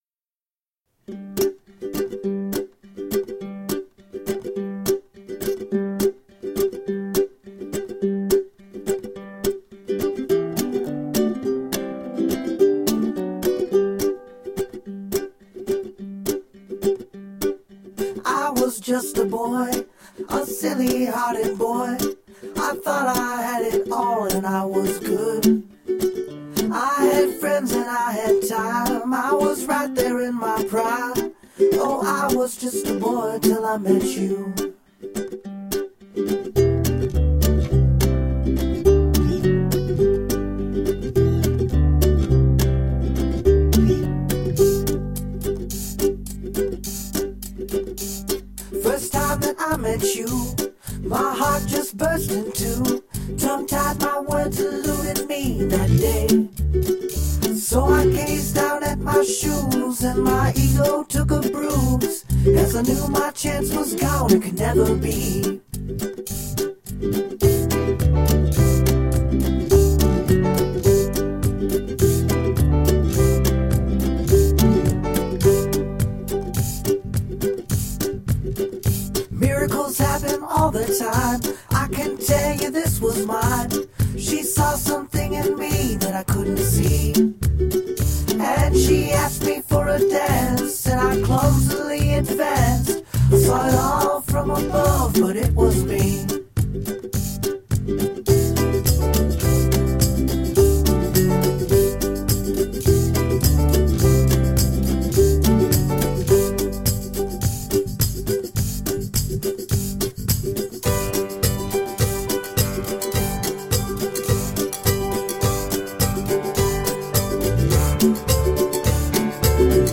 Uptempo, happy, banjo-driven acoustic folk pop.
Features a lot of ukulele, banjo, and acoustic guitars.
Tagged as: Alt Rock, Folk, Folk-Rock, Folk